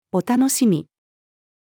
お楽しみ-female.mp3